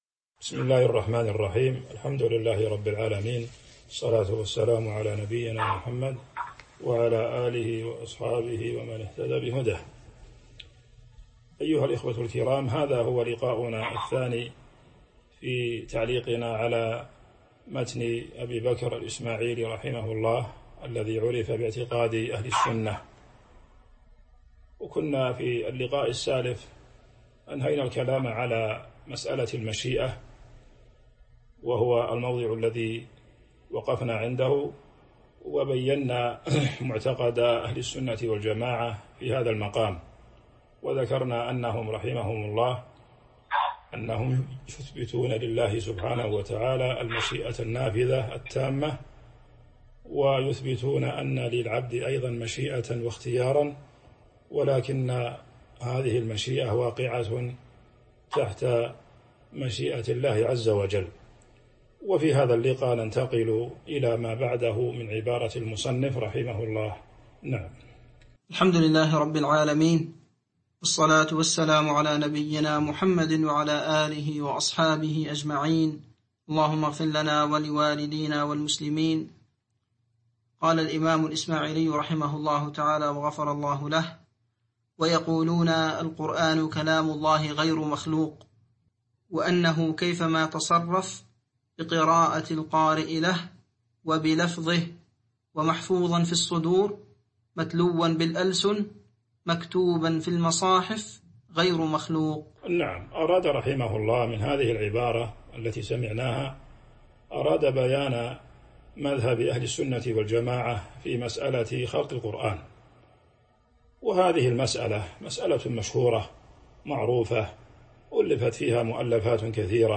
تاريخ النشر ٢٢ صفر ١٤٤٢ هـ المكان: المسجد النبوي الشيخ